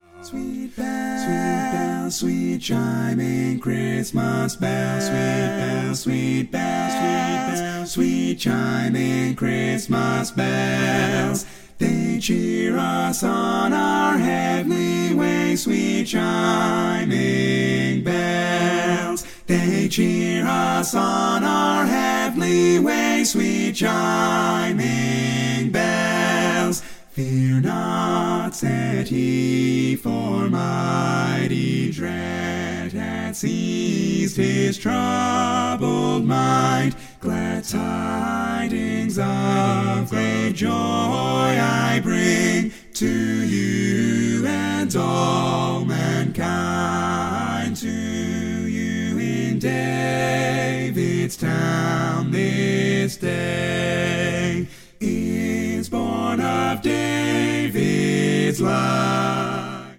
Full mix only